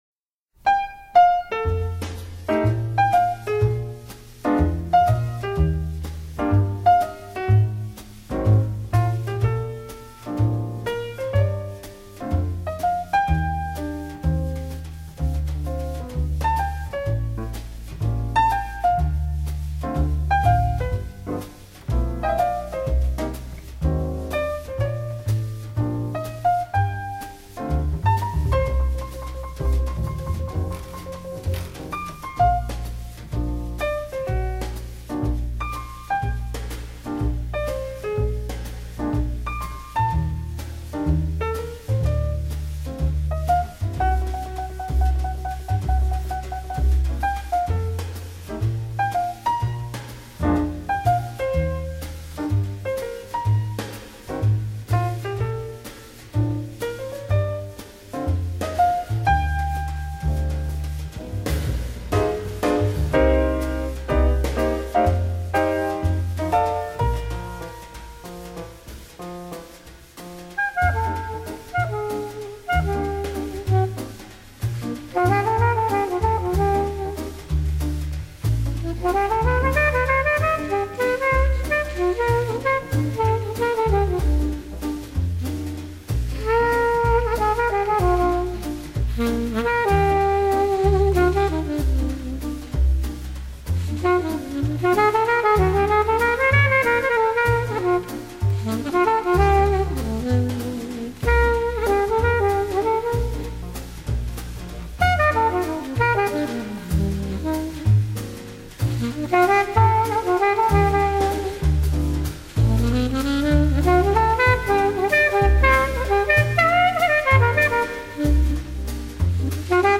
蓝调爵士